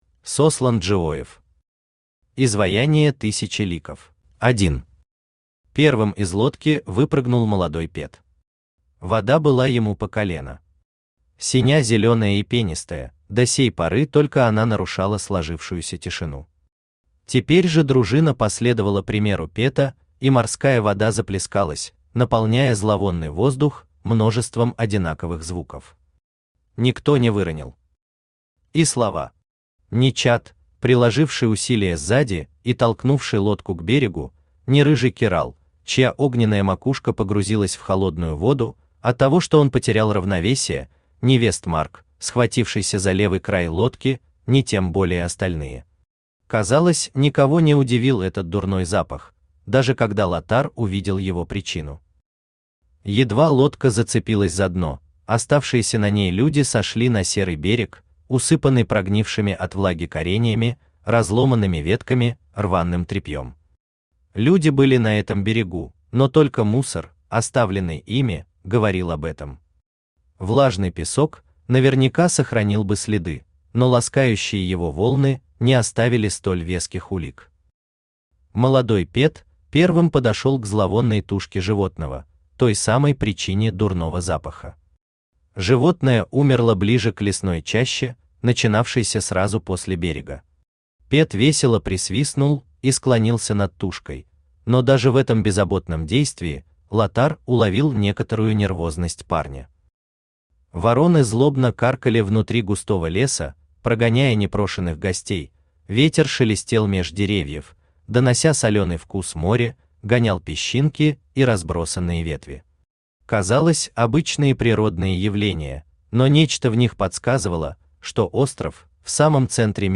Аудиокнига Изваяние тысячи ликов | Библиотека аудиокниг
Aудиокнига Изваяние тысячи ликов Автор Сослан Владиславович Джиоев Читает аудиокнигу Авточтец ЛитРес.